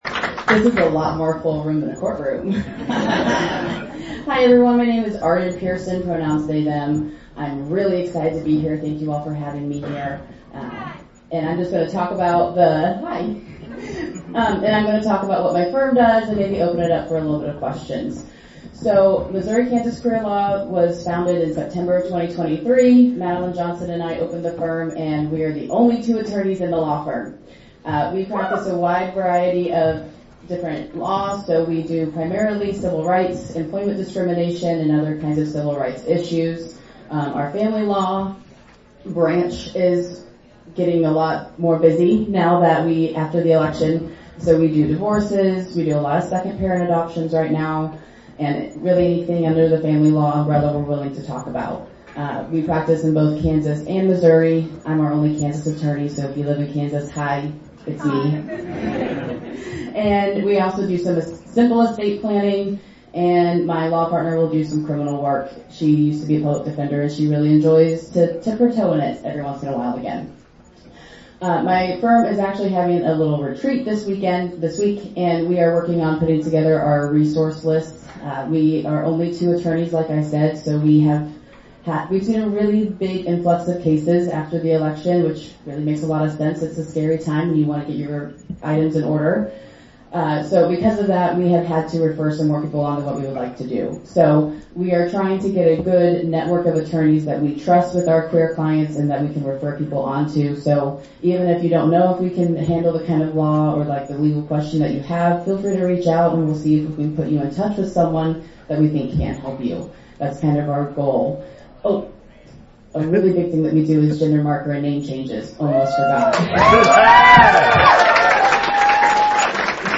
On Sunday night in Kansas City the LGBTQIA+ community in Kansas City, Missouri held a Townhall in a supportive establishment on Main Street. More than 150 people attended the over two-hour standing room only event.